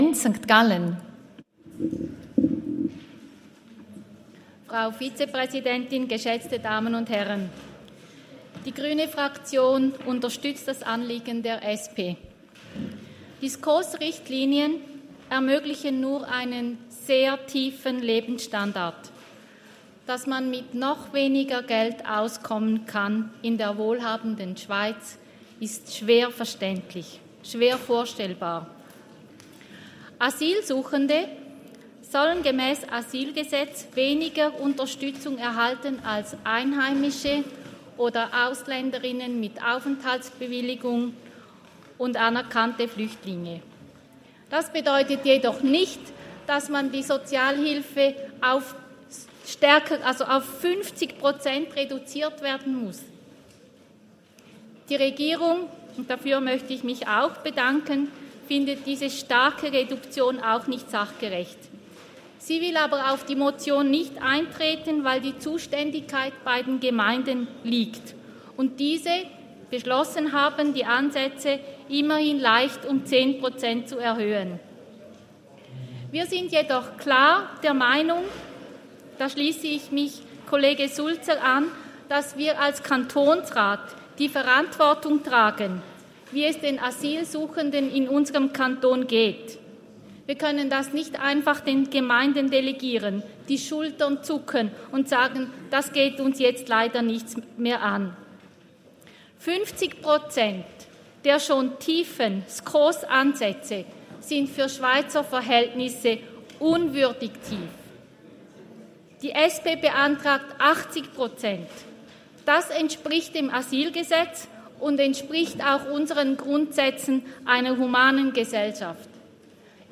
Session des Kantonsrates vom 13. bis 15. Februar 2023, Frühjahrssession
Benz-St.Gallen (im Namen der GRÜNE-Fraktion): Auf die Motion ist einzutreten.